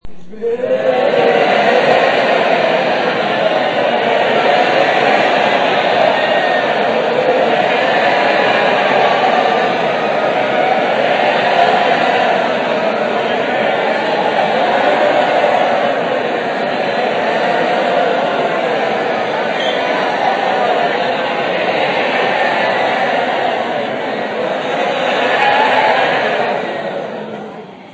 Tags: grindcore comedy horror gore insult